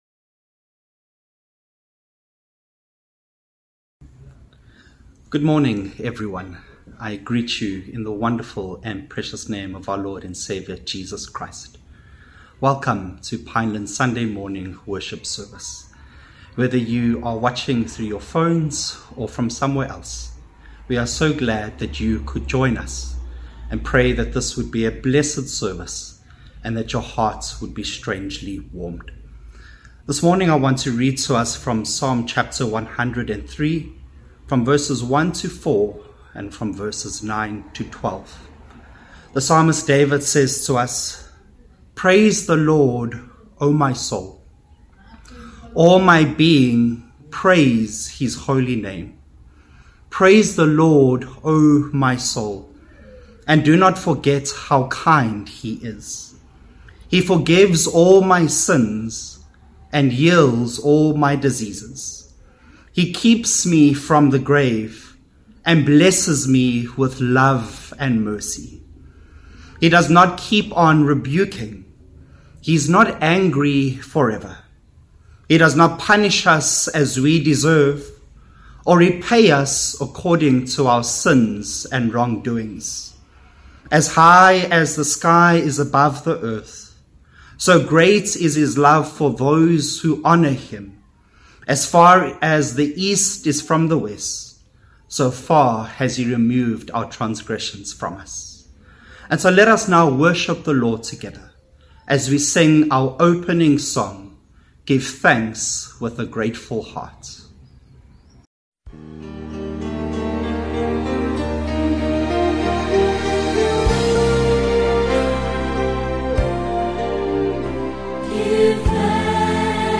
Sermon 18th August